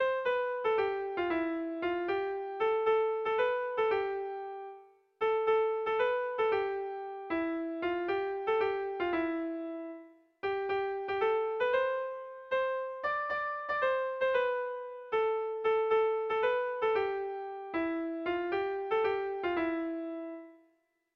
Gabonetakoa